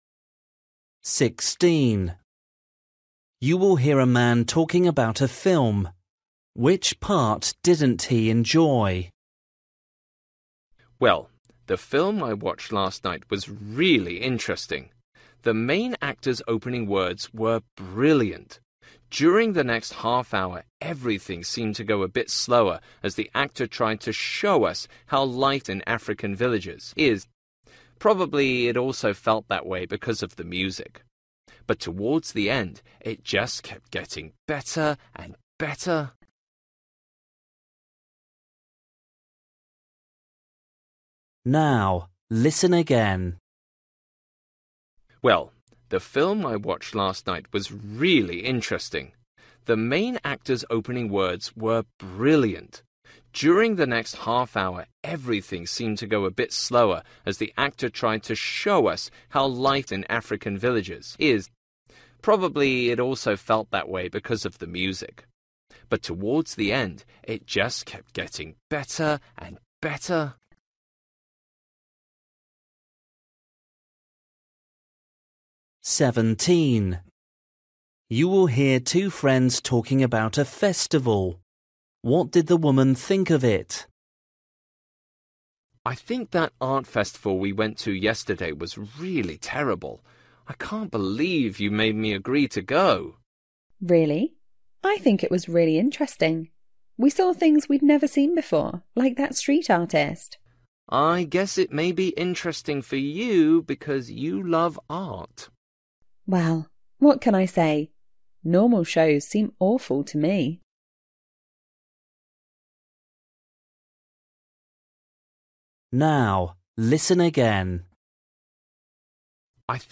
Listening: everyday short conversations
16   You will hear a man talking about a film. Which part didn’t he enjoy?
17   You will hear two friends talking about a festival. What did the woman think of it?
19   You will hear a coach talking to his players. What is he telling them?